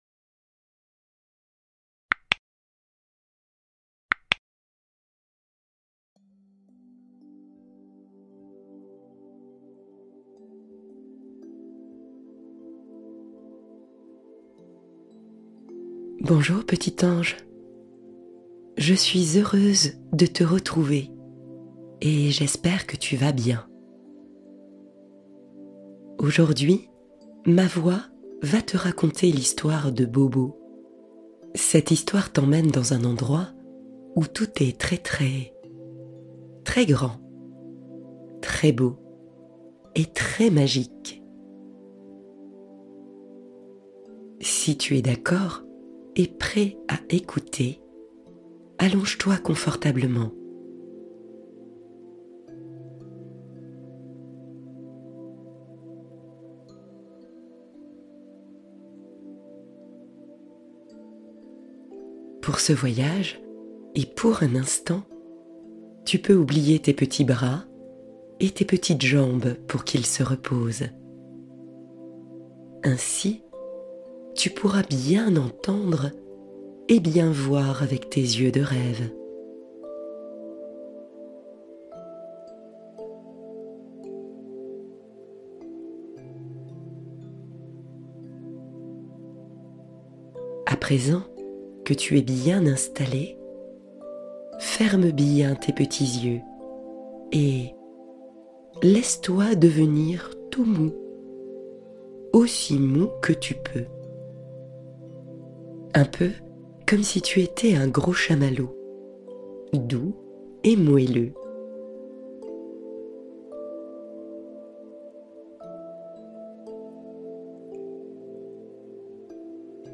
La tête dans les étoiles — Voyage imaginaire pour enfants et parents